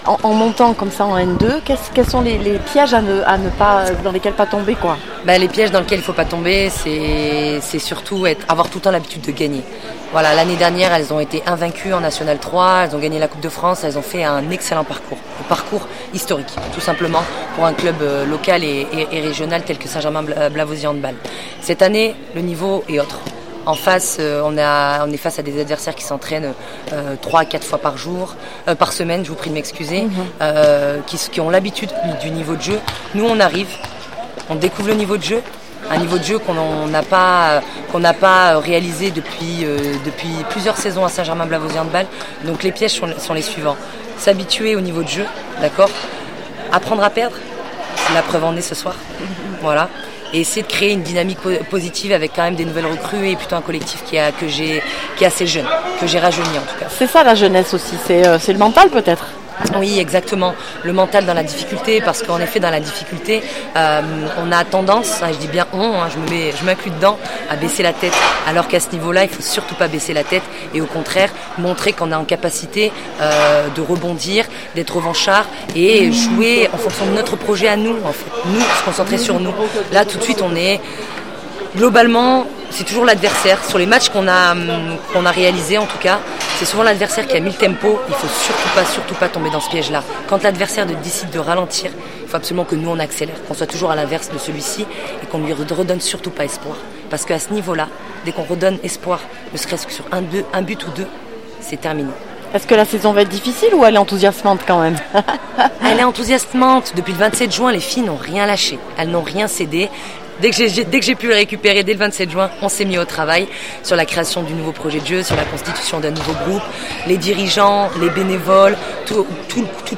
national 2 handball féminin st Germain Blavozy 31-37 st Genist Laval réactions après match 091022